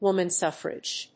アクセントwóman súffrage